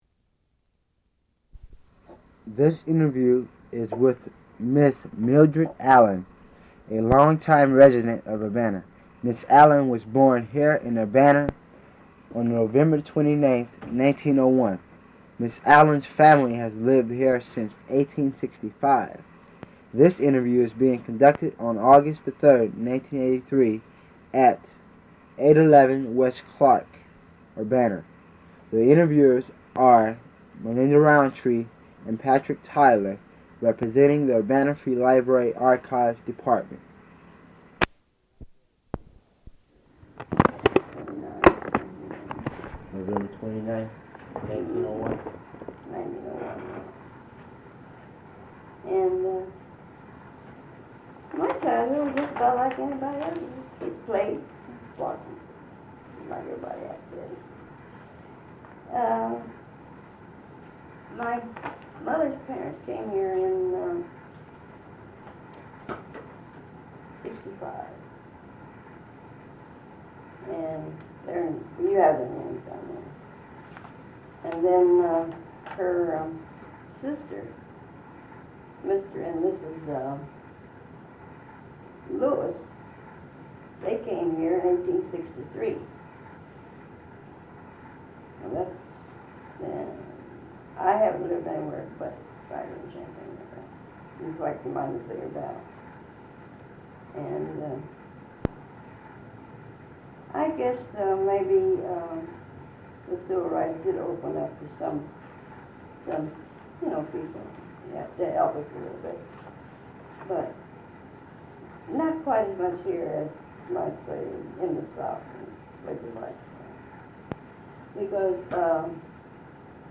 Oral History